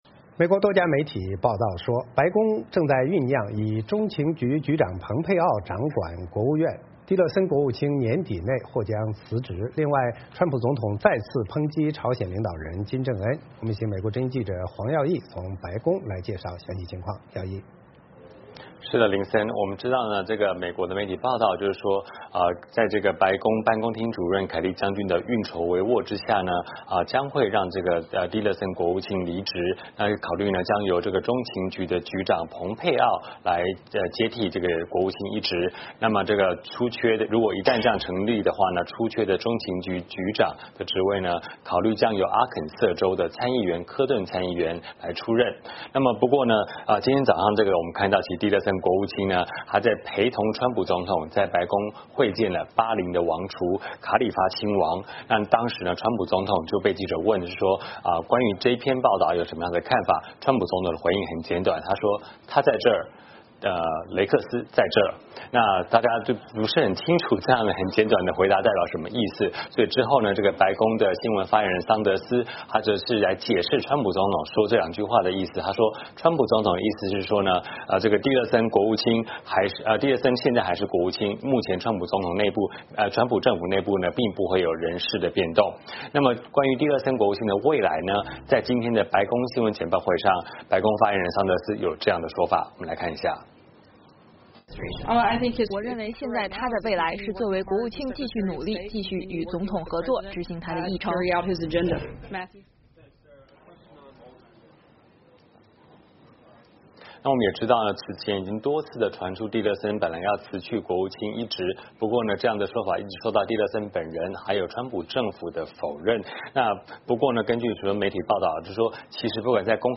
VOA连线：白宫要换国务卿蒂勒森，川普再批“小火箭人”